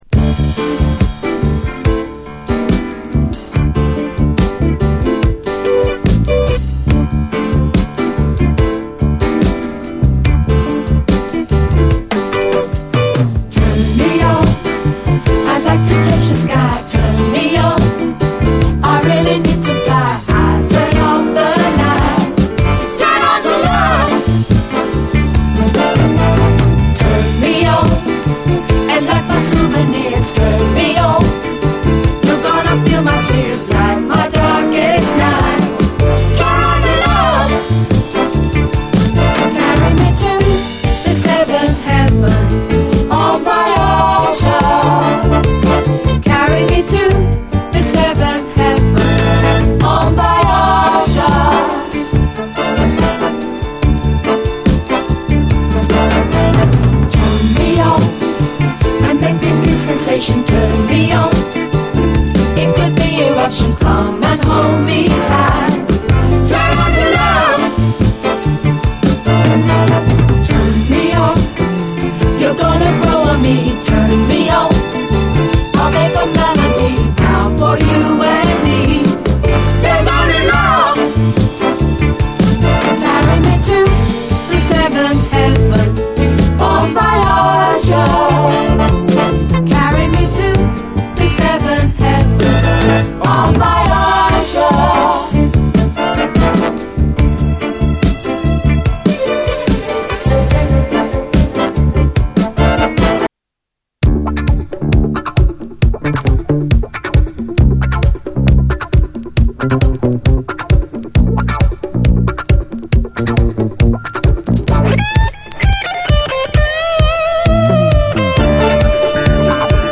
SOUL, DISCO
70’Sフレンチ・ディスコ！
ネットリとムーディーなスローモー・メロウ・ディスコ
流麗なストリングスを纏ったインスト・ロッキン・ディスコ